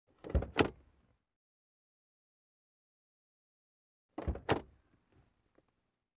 Звуки двери автомобиля